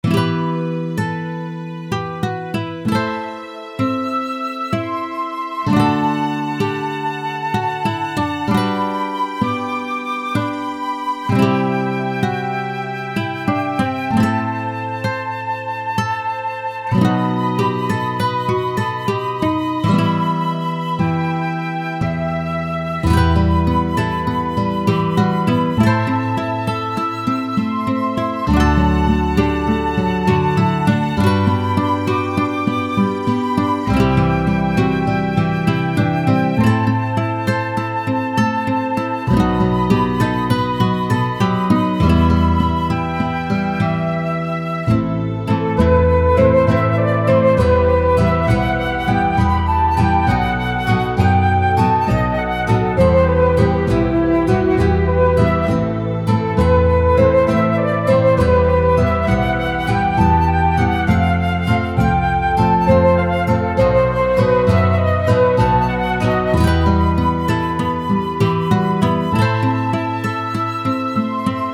ogg(L) 哀愁 ギター クラシカル
じっくり聴かせるクラシックギター風。